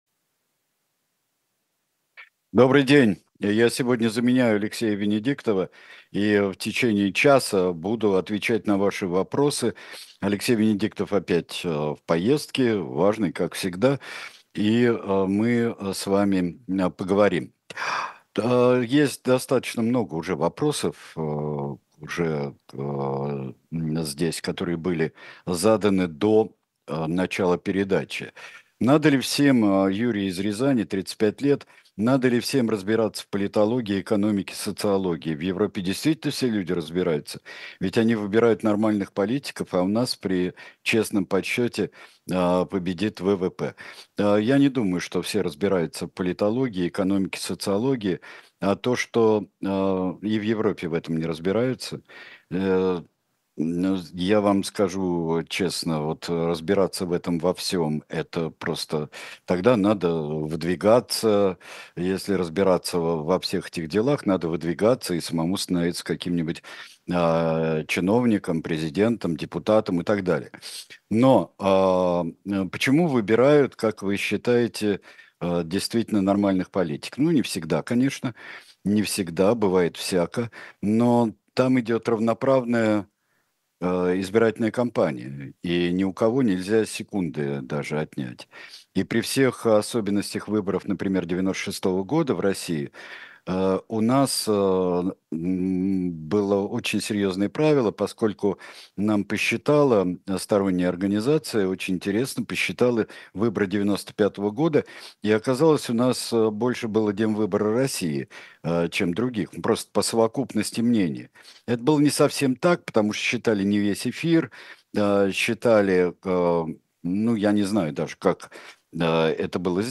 Сергей Бунтман отвечает на ваши вопросы в прямом эфире